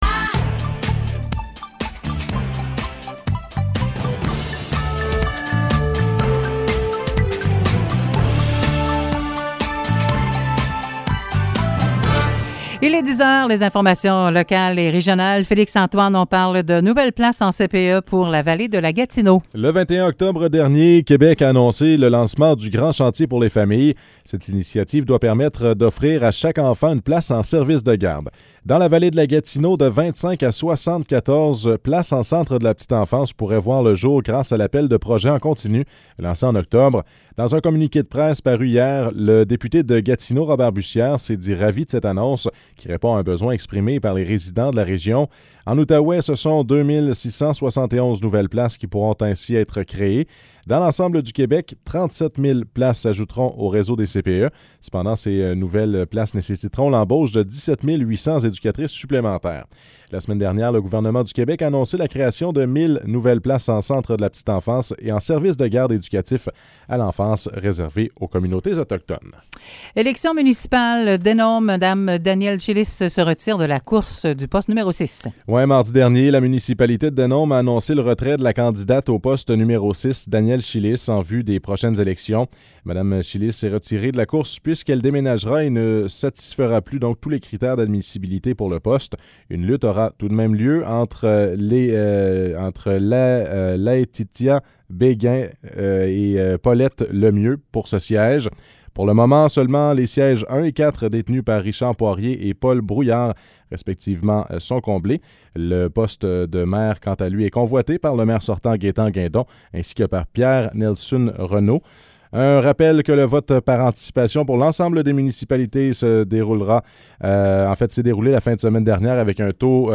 Nouvelles locales - 2 novembre 2021 - 10 h